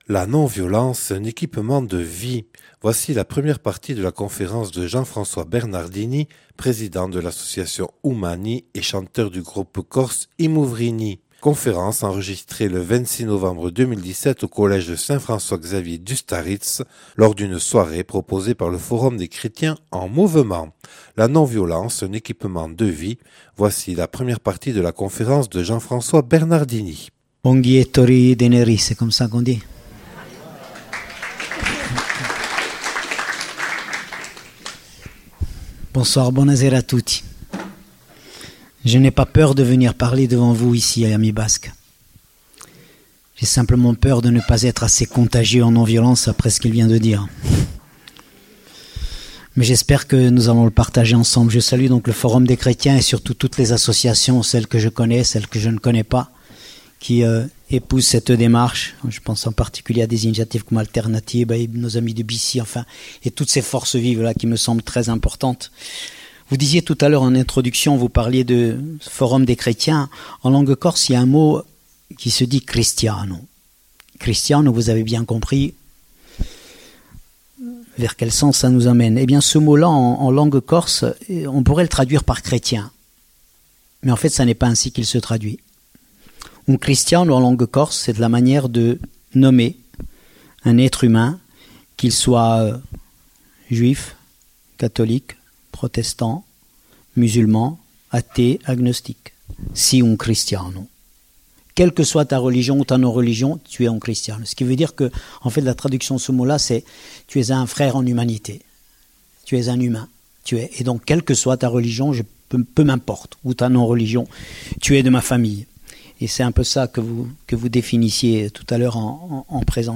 Conférence présentée par Jean-François Bernardini, président de l’association Umani et chanteur du groupe corse I Muvrini. (Enregistrée le 26/11/2017 au collège Saint François-Xavier à Ustaritz).